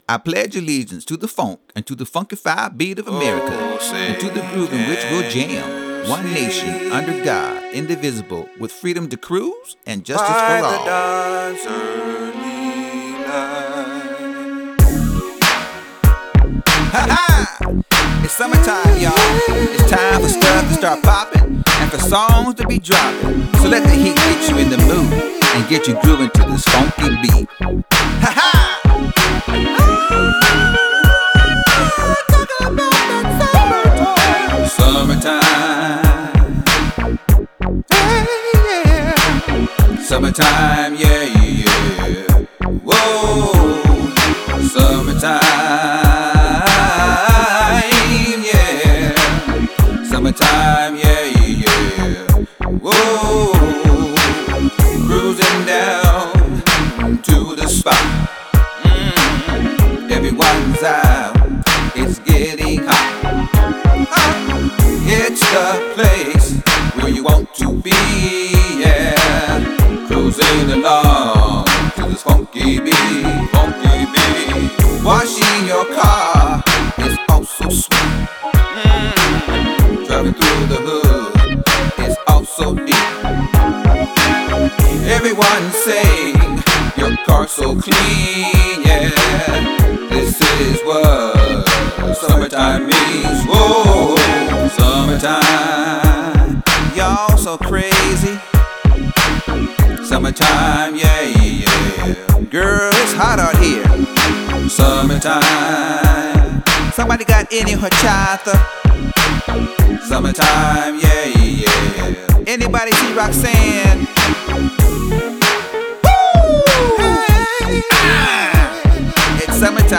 Funk
Backing Vocals